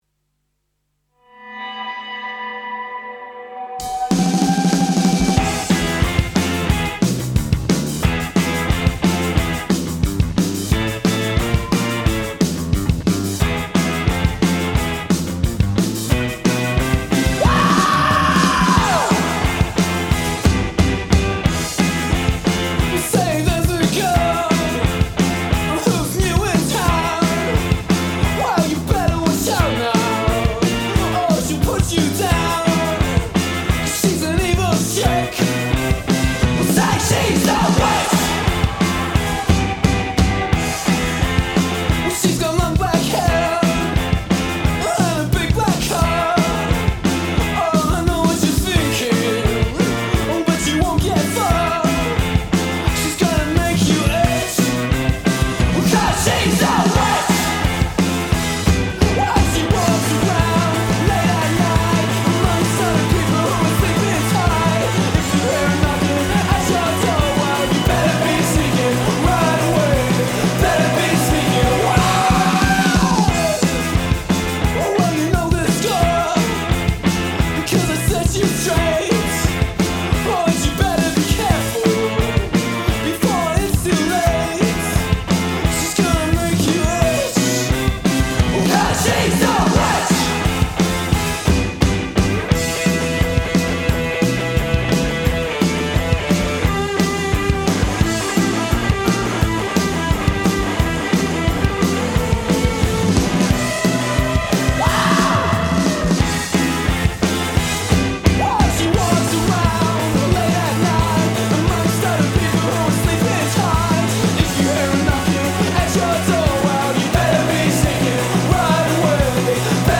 just down right punky song